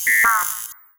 sci-fi_code_fail_05.wav